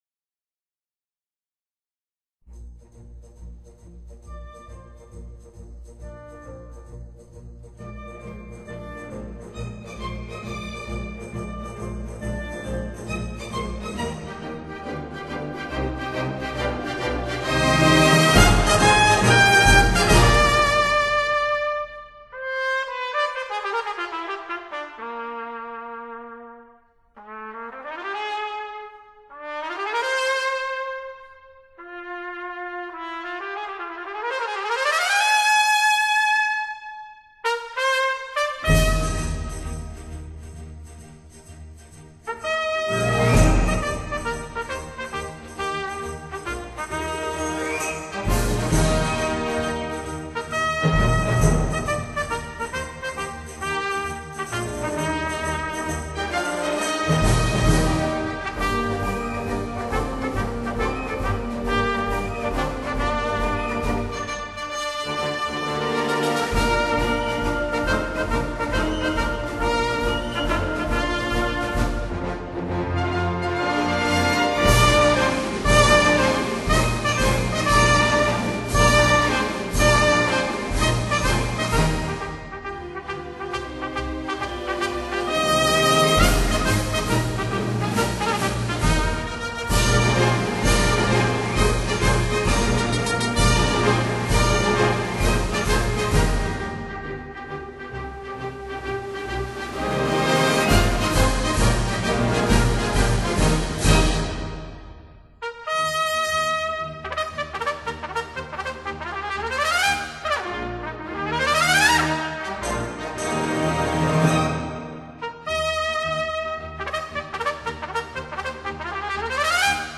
這張CD中的主奏小喇叭技巧出神入化，吹奏的速度要多快就多快，音色的變化要多少種就有多少種。
請切記我所說的：小喇叭即使再嘹亮也不能尖瘦刺耳，它還是會有厚度與溫暖的音質。